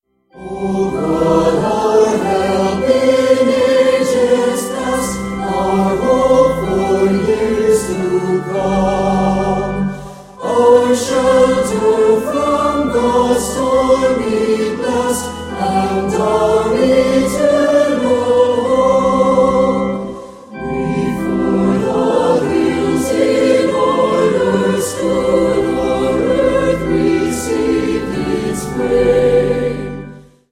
mit leichter instrumentaler begleitung
• Sachgebiet: Praise & Worship